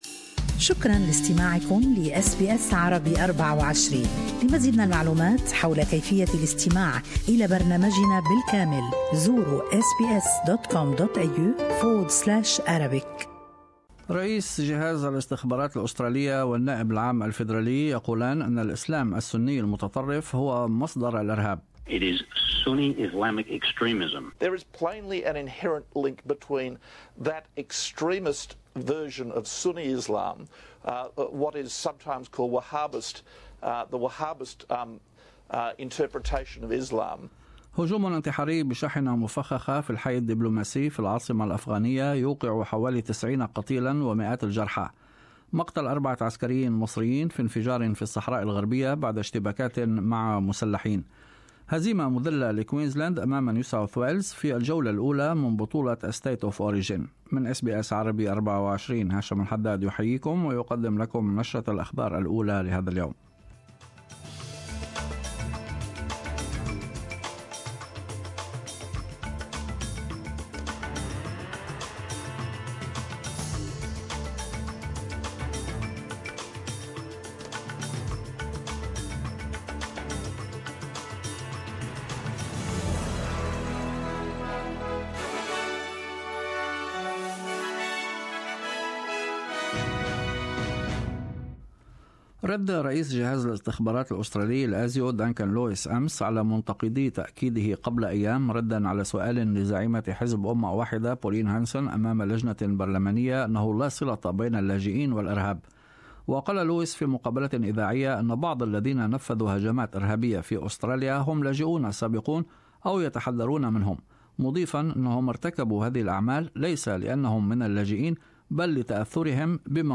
Australian and world news in morning news bulletin.